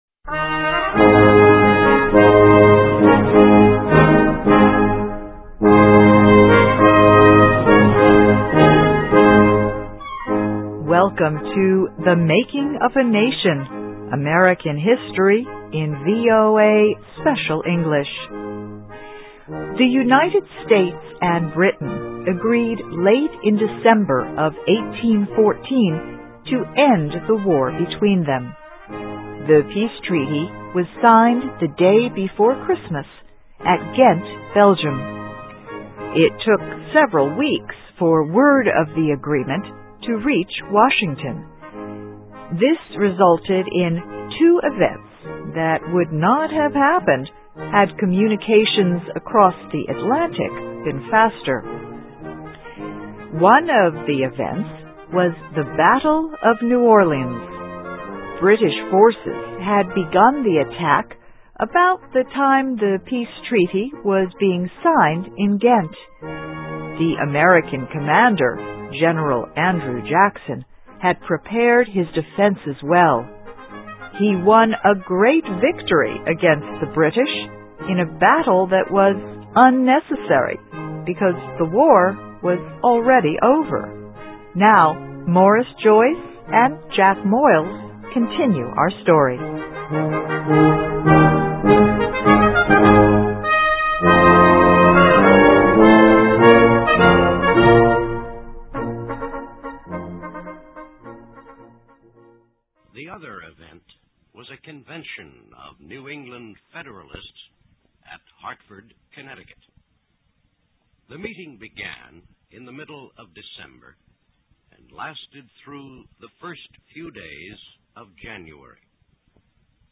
American History: War of 1812 Ends With Treaty of Ghent (VOA Special English 2008-09-03)
Listen and Read Along - Text with Audio - For ESL Students - For Learning English